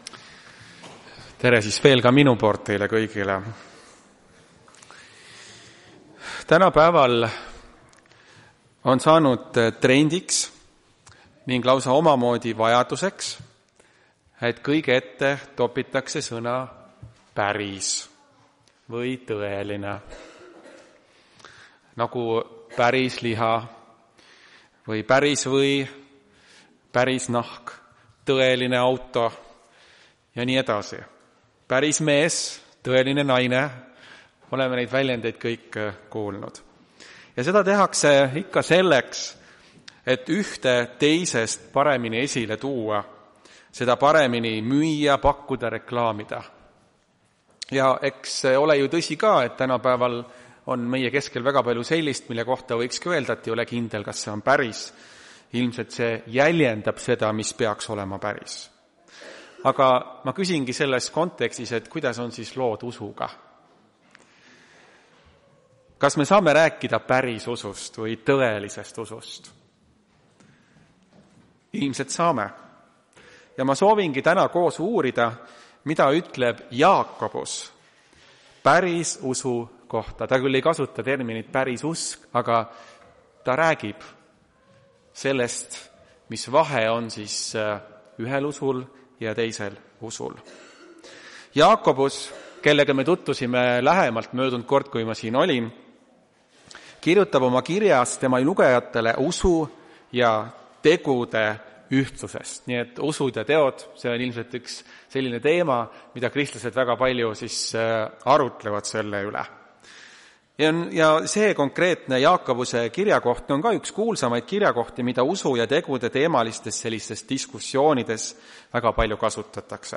Tartu adventkoguduse 21.02.2026 teenistuse jutluse helisalvestis.